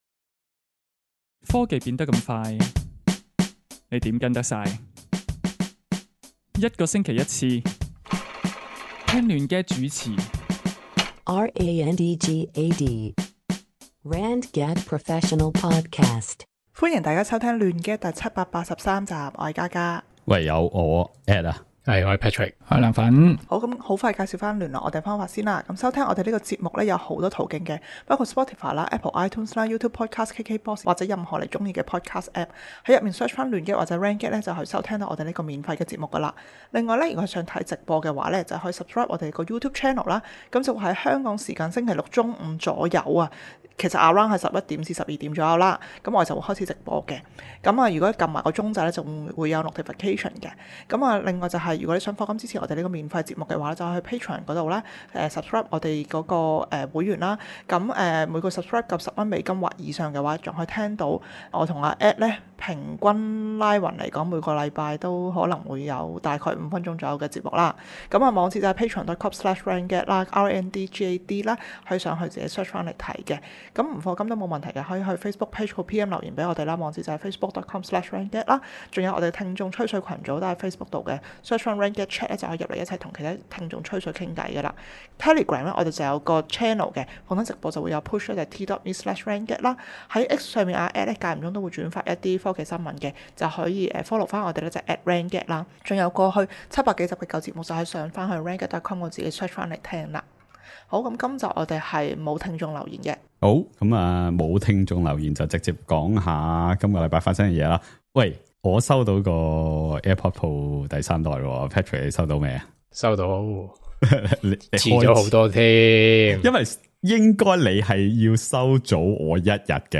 搜羅最新科技資訊、數碼產品，由四位主持，從不同立場出發，以專業角度分析，每星期一集既網上電台節目 - 亂gad！